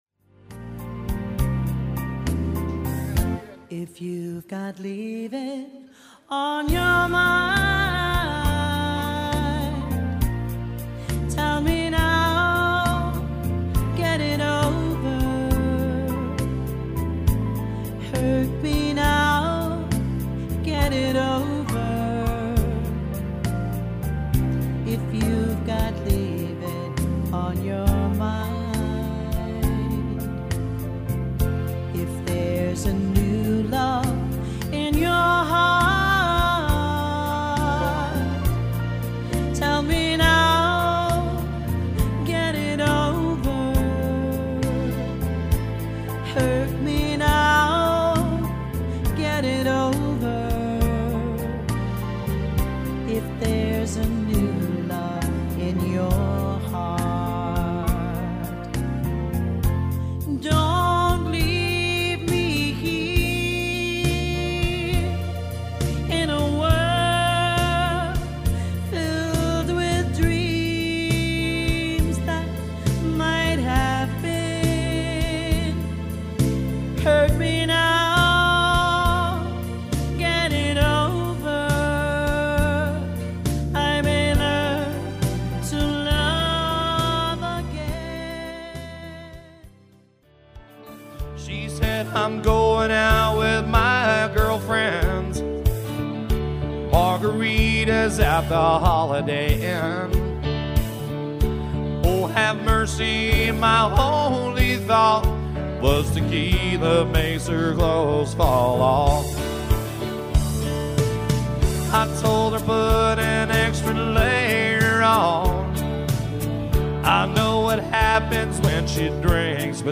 Country
The mix may not always be perfect, you may here some
minor distortion, you will here some background noise.